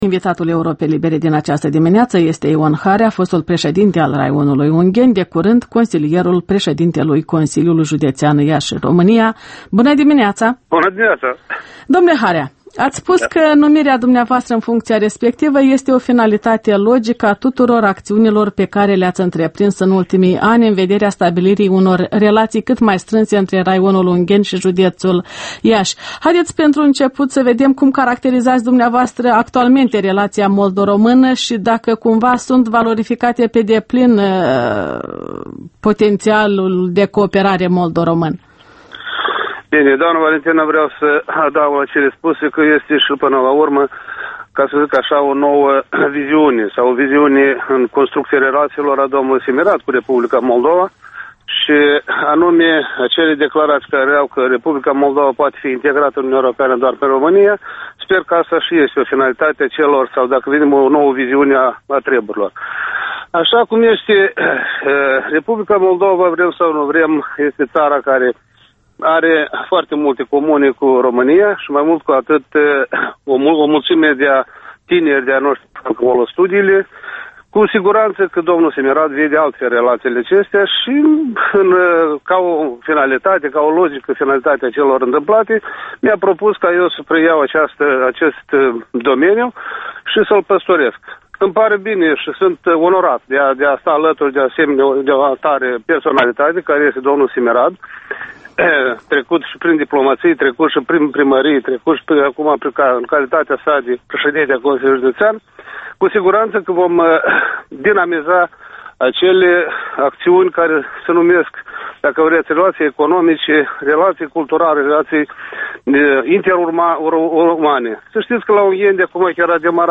Interviul matinal la Europa Liberă